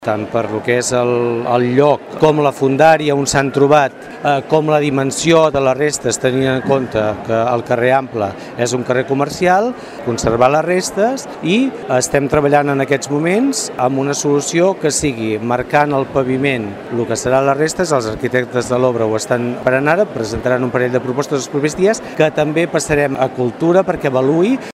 Enric Marquès, alcalde de la Bisbal